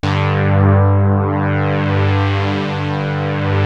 JUP.8 G3   2.wav